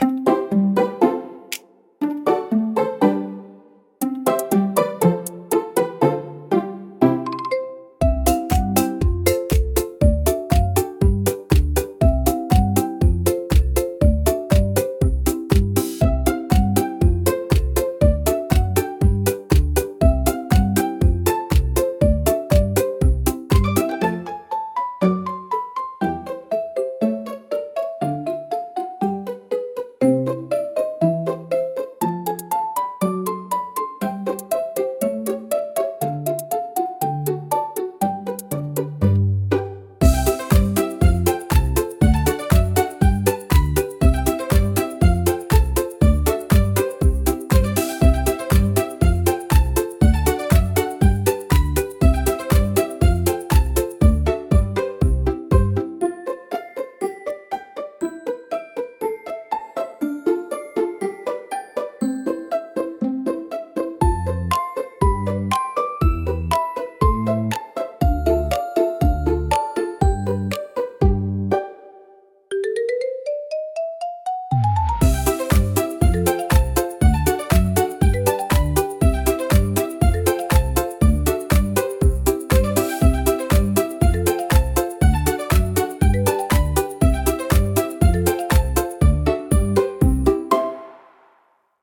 聴く人に安心感と温かさを届け、自然で可愛らしい空間を演出します。